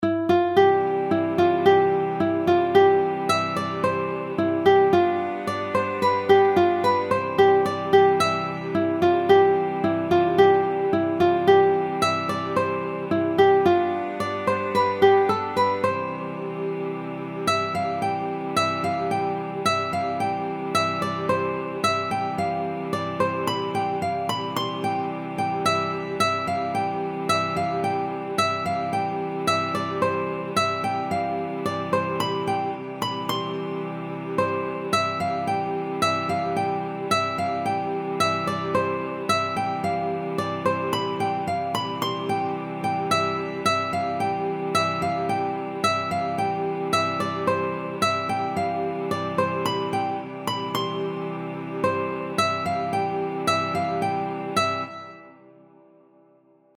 Mélodies et accords